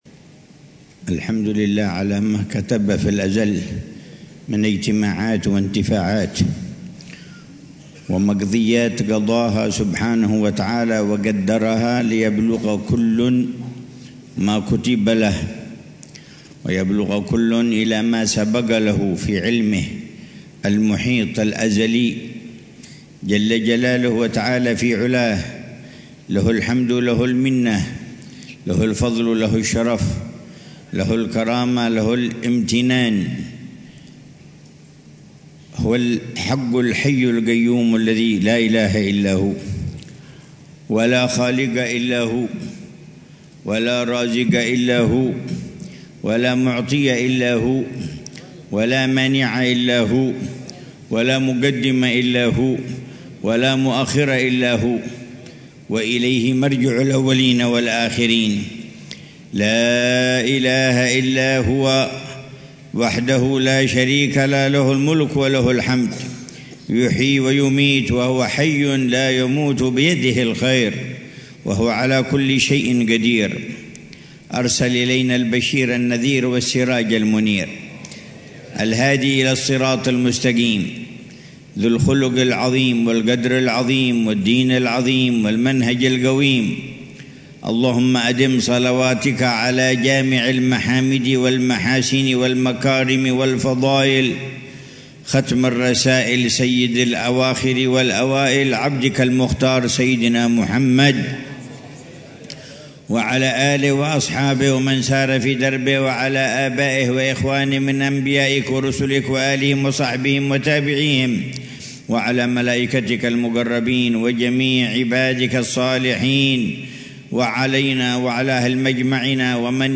محاضرة العلامة الحبيب عمر بن محمد بن حفيظ في مجلس توديع طلاب الدورة التعليمية الحادية والثلاثين بدار المصطفى بتريم للدراسات الإسلامية، صباح السبت 15 صفر الخير 1447هـ بعنوان: